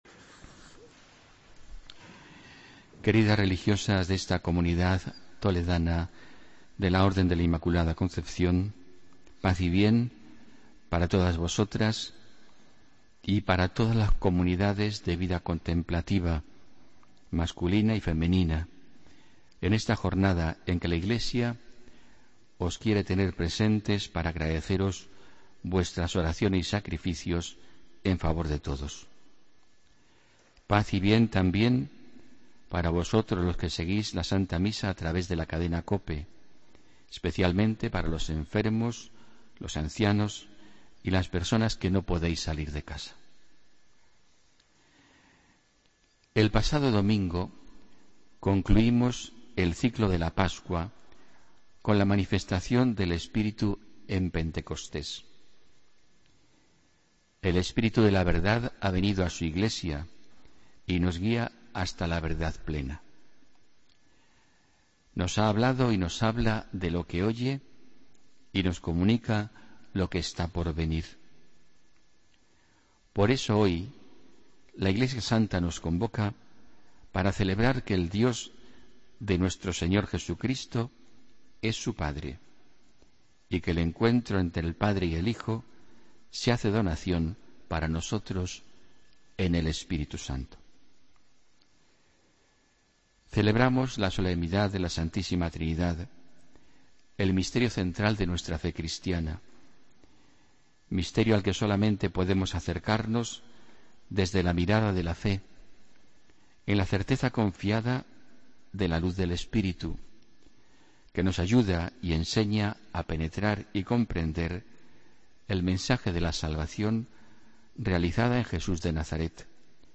Homilía del domingo 22 de mayo de 2016